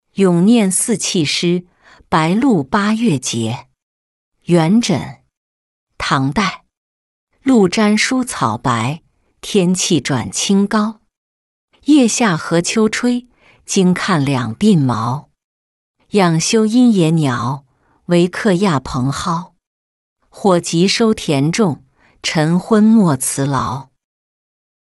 咏廿四气诗·白露八月节-音频朗读